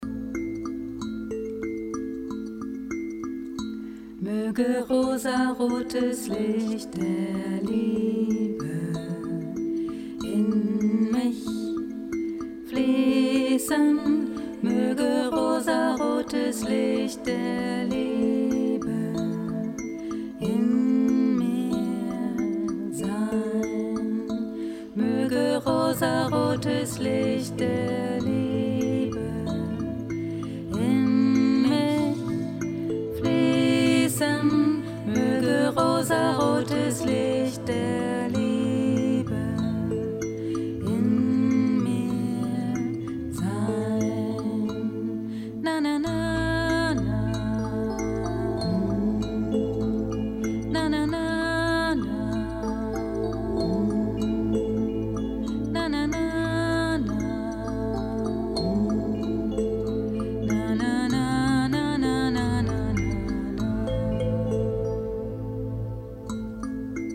melodic
relaxing
Genre: Healing Songs